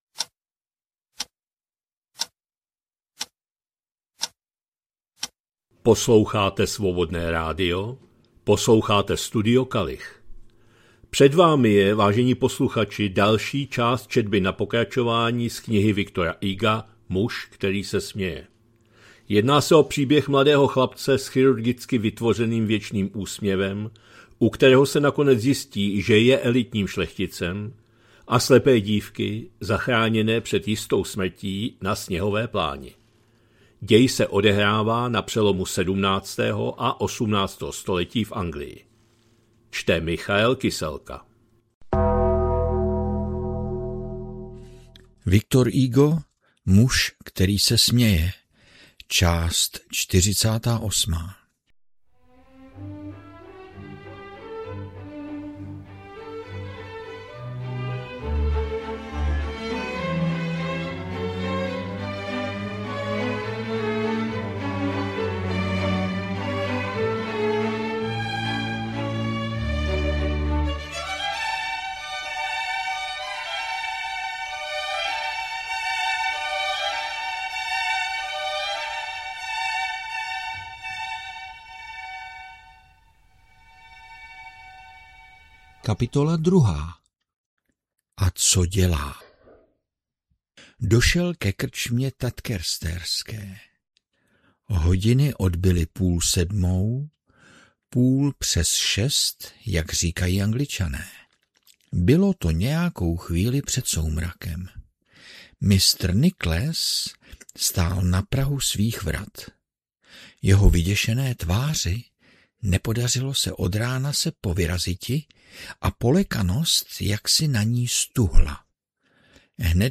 2026-02-13 – Studio Kalich – Muž který se směje, V. Hugo, část 48., četba na pokračování